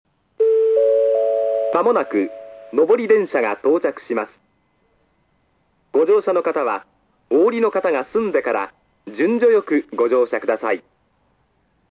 ●スピーカー：ソノコラム・小
●音質：D
２番線 接近放送・上り（福岡天神方面）　(58KB/11秒）
男性声のみの行先を言わない簡易放送ですが、「上り」「下り」の２パターンあります。